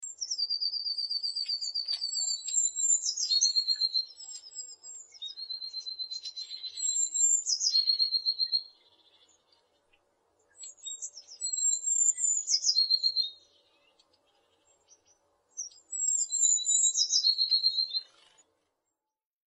Stimme Blaumeise
Blaumeise.mp3